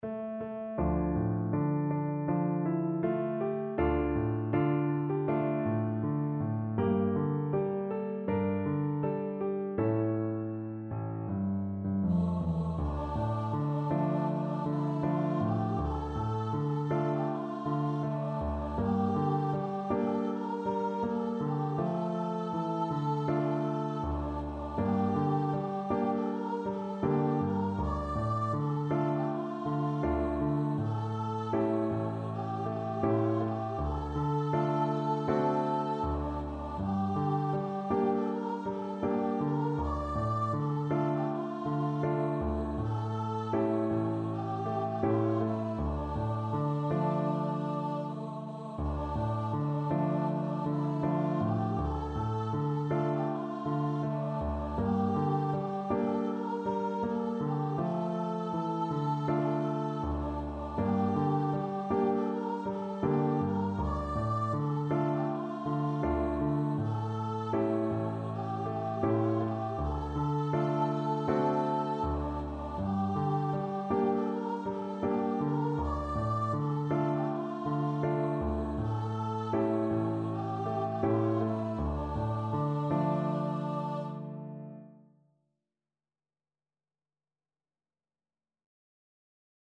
Voice
4/4 (View more 4/4 Music)
A4-D6
D minor (Sounding Pitch) (View more D minor Music for Voice )
Andante espressivo
Traditional (View more Traditional Voice Music)